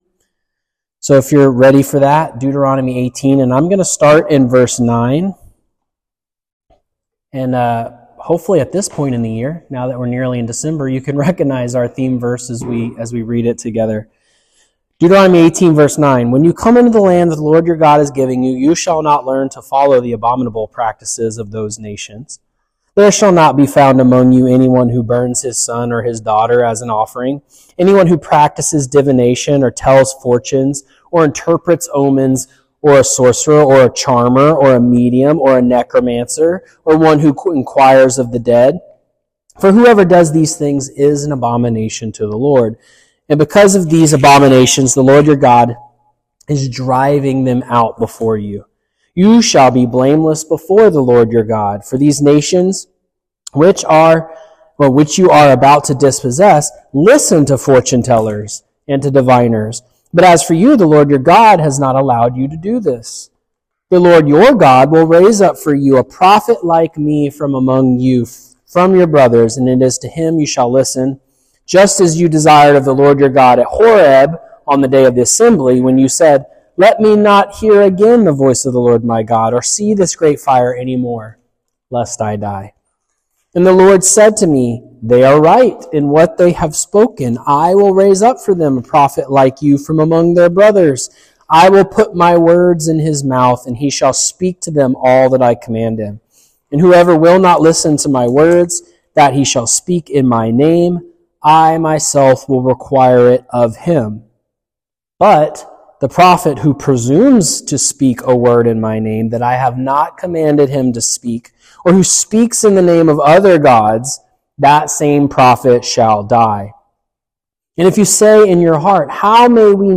A lesson from our 2025 Annual theme sermon series that explores the meaning, fulfillment, and application of Deuteronomy 18.18.